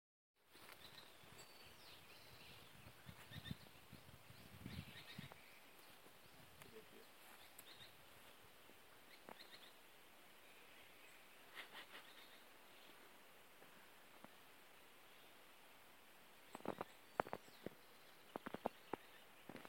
Spix´s Spinetail (Synallaxis spixi)
Life Stage: Adult
Location or protected area: Delta del Paraná
Condition: Wild
Certainty: Recorded vocal
Pijui_plomizo.mp3